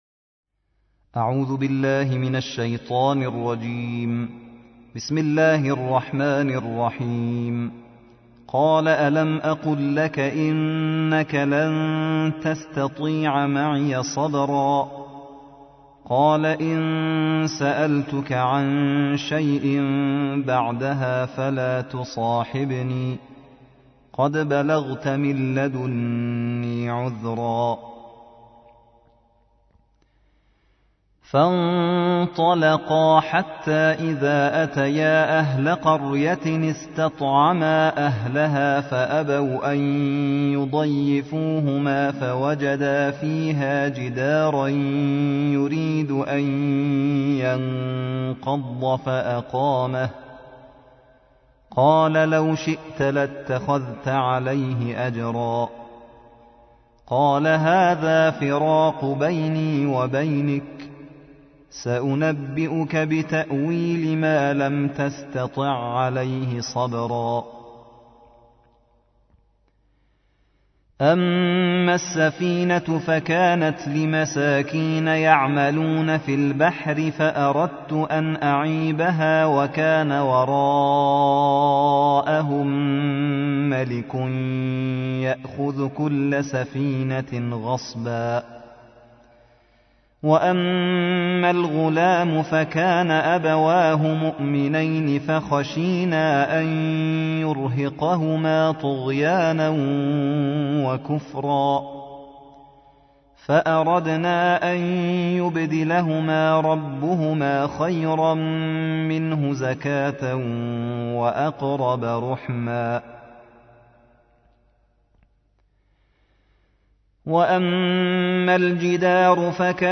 الجزء السادس عشر / القارئ